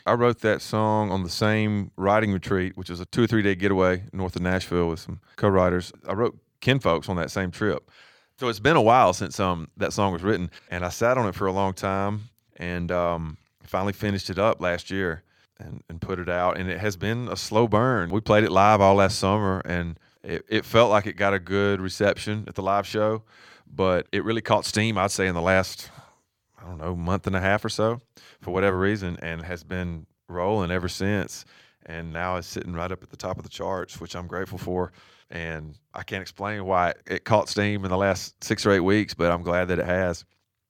Sam Hunt talks about his latest smash, "Outskirts," which has vaulted to the top of the country charts.